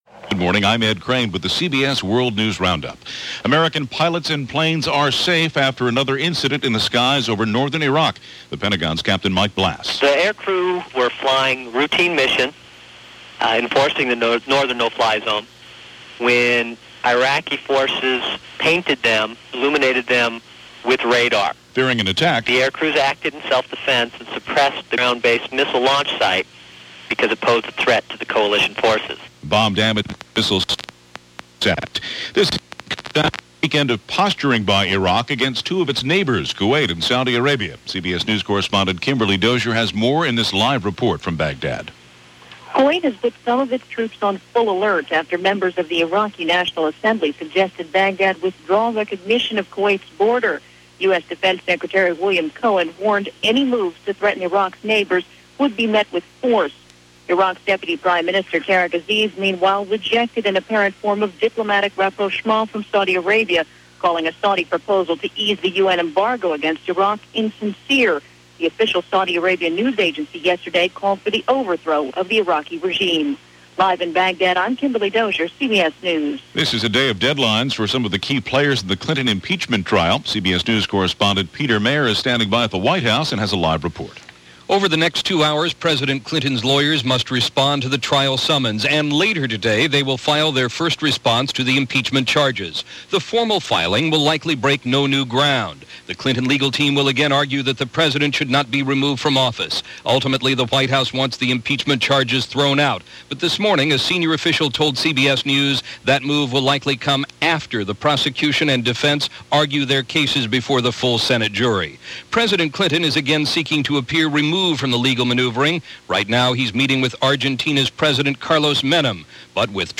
January 11, 1999 – CBS World News Roundup – Gordon Skene Sound Collection –